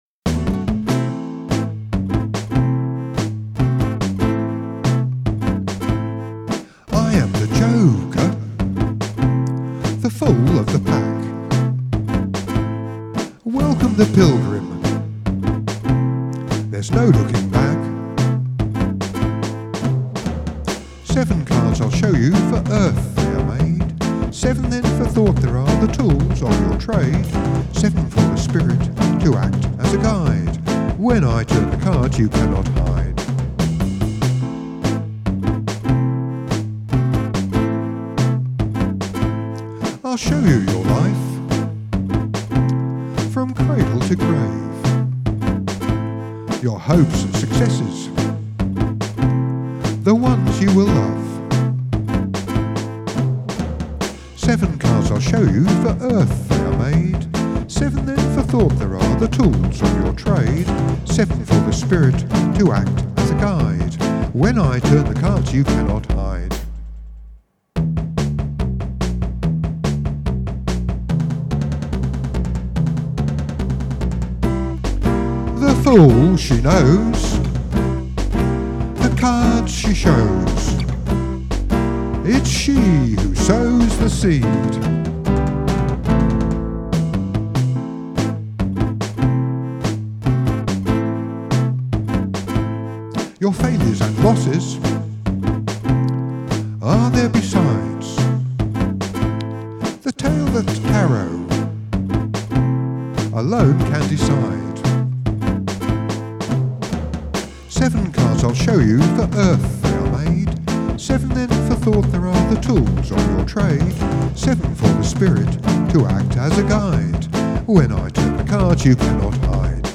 joker-vocal-1.mp3